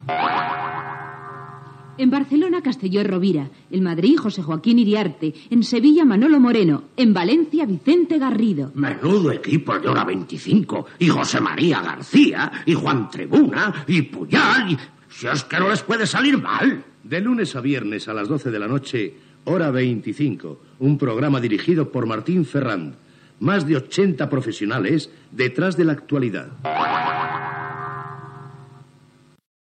Promoció de l'estrena del programa amb els noms de l'equip.